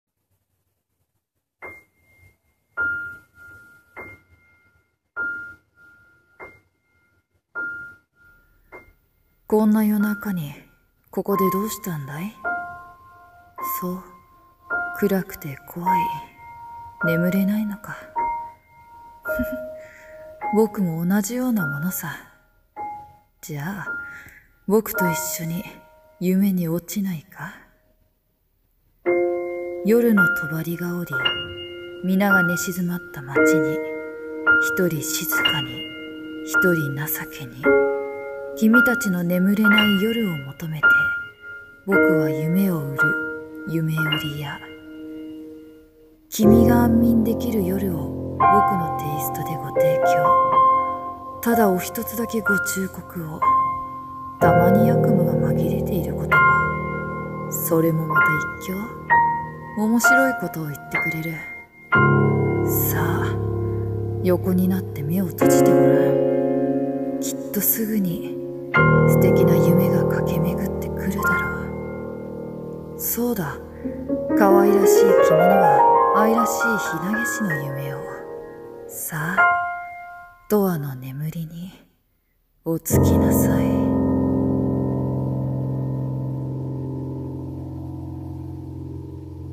【1人声劇】夢売屋